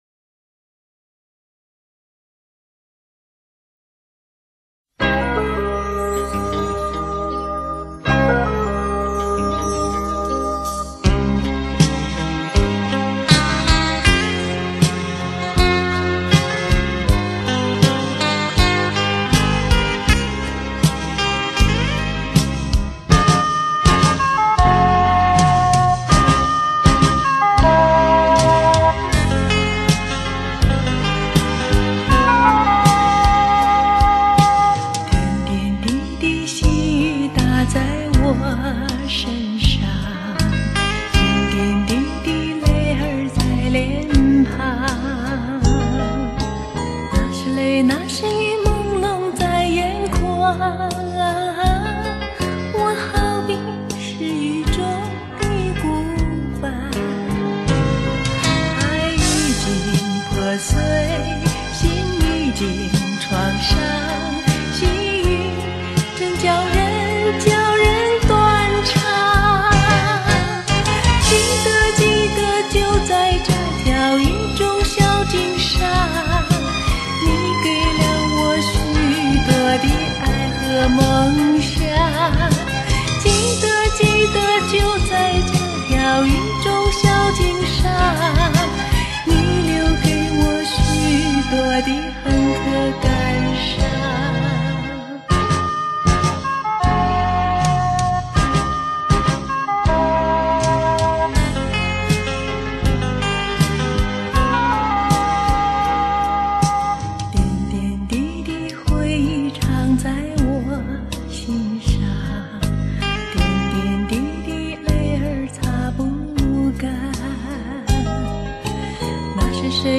多了份妩媚 同样的婉转